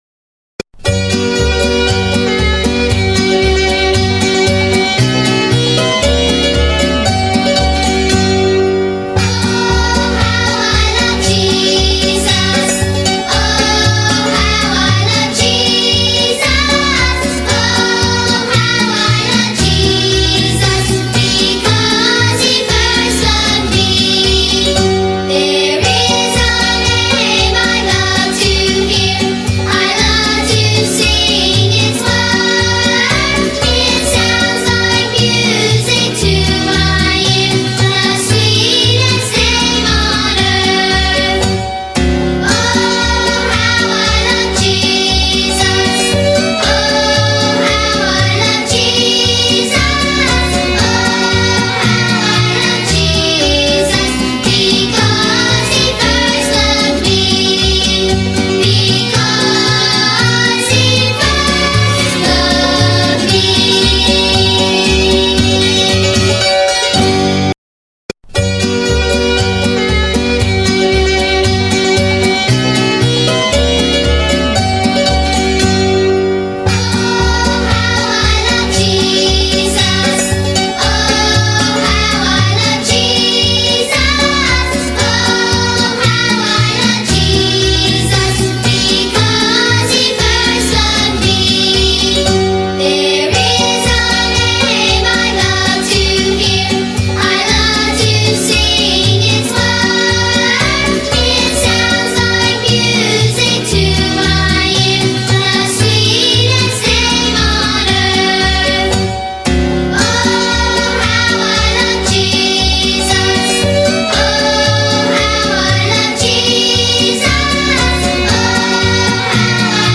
视频里有动作演示，音频里歌会自动重复三遍。
诗歌第一遍
诗歌第二遍
诗歌第三遍